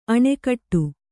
♪ aṇigeḍu